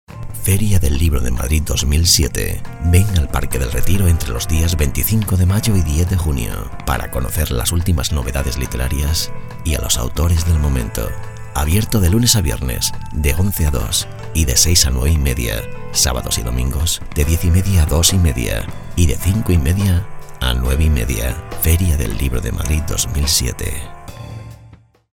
locutor español.